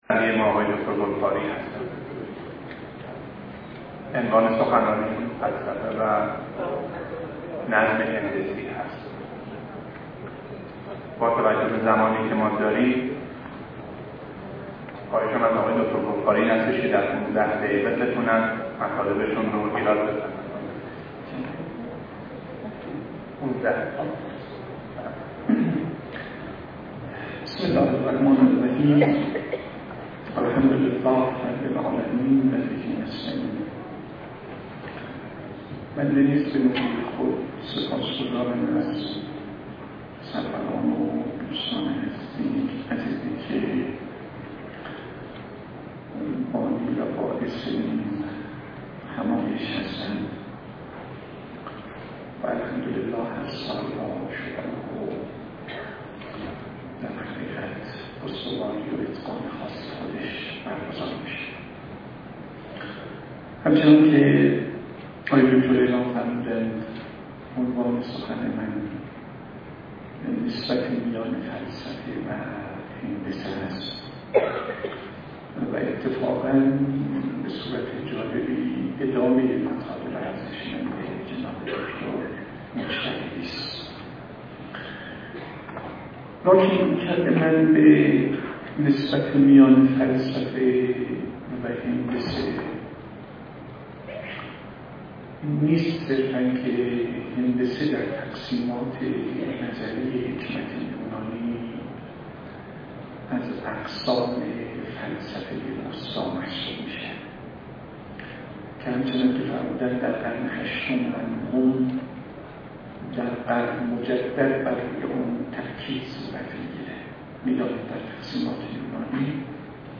سخنرانی
این همایش به همت بنیاد حکمت اسلامی صدرا ۳۱ اردیبهشت ماه ۹۴ در این مرکز برگزار شد.